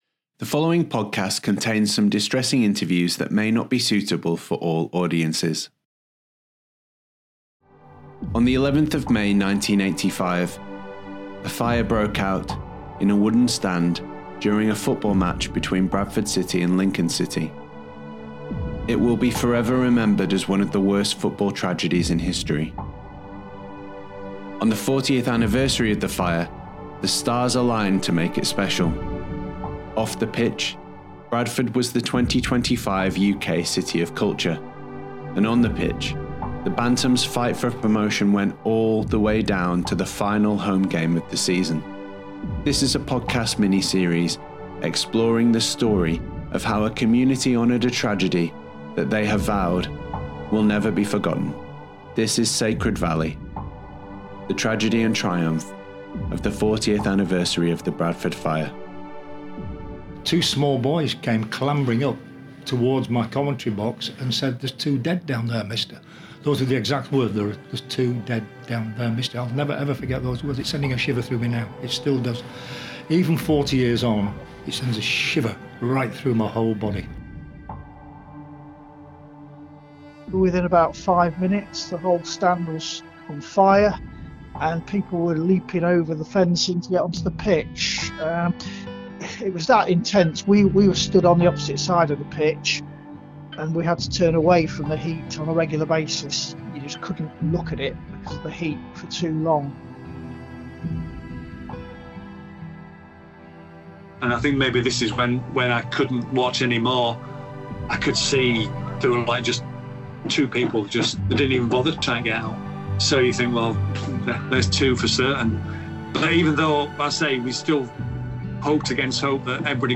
** Content Warning ** This podcast contains some distressing interviews that may not be suitable for all audiences. On the 11th of May 1985, a fire broke out in a wooden stand during a football match between Bradford City and Lincoln City.